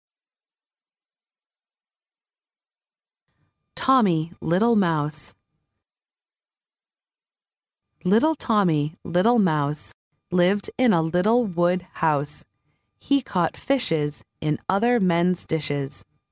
幼儿英语童谣朗读 第27期:汤米是只小老鼠 听力文件下载—在线英语听力室